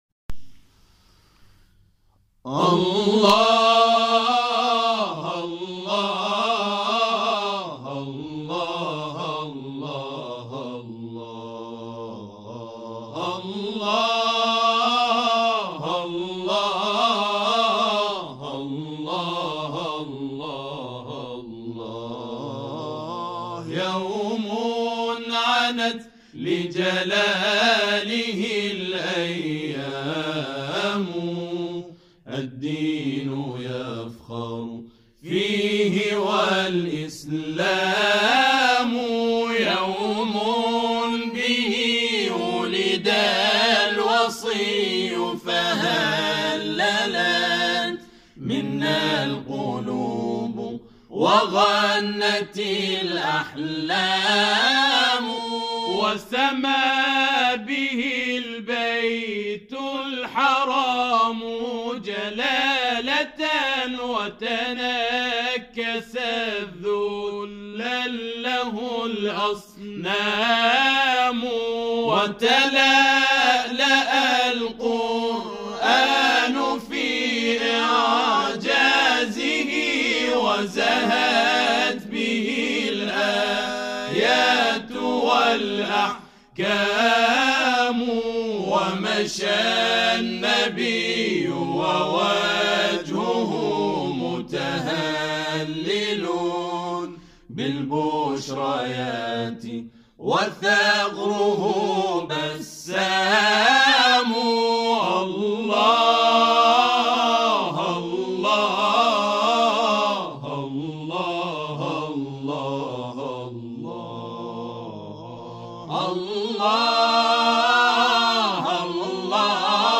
به گزارش خبرنگار فرهنگی باشگاه خبرنگاران تسنیم «پویا» گروه «العباد» خوزستان که در مرحله مقدماتی بیست‌ویکمین دوره مسابقات مدیحه‌سرایی و همخوانی مقام نخست را از آن خود کرده است 6 عضو دارد.